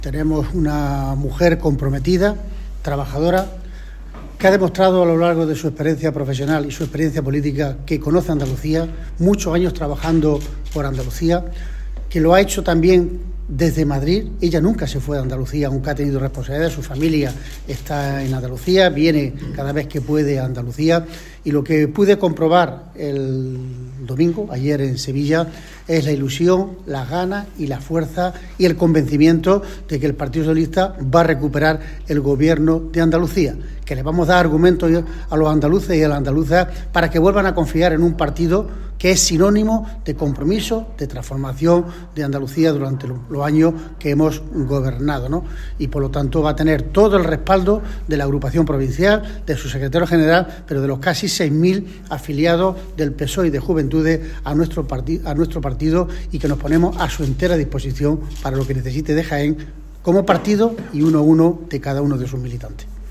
En declaraciones a los medios de comunicación, Reyes valoró la llegada de María Jesús Montero a la Secretaría General del PSOE de Andalucía como la de “una mujer comprometida, trabajadora y que ha demostrado a lo largo de su experiencia profesional y política que conoce Andalucía”.
Cortes de sonido